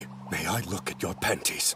one piece brook panties meme sound from all sound effects
one-piece-brook-panties-meme-sound